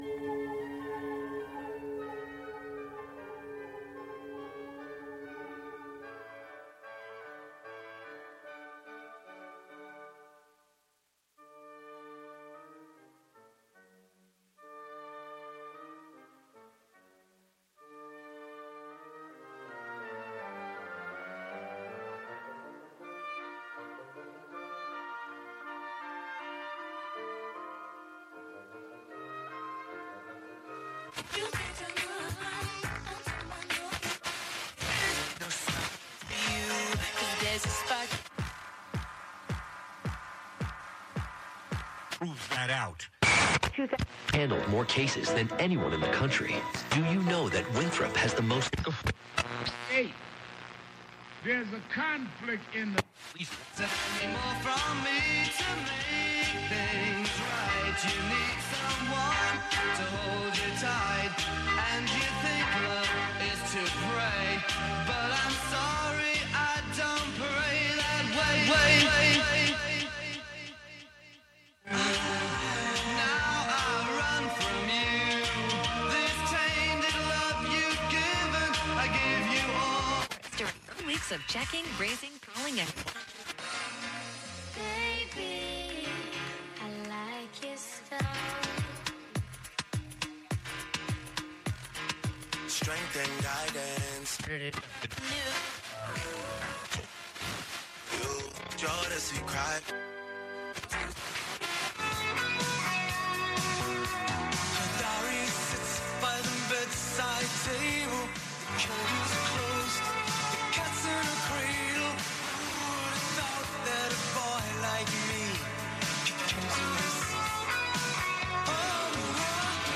Live from Brooklyn, remixing radio on the radio.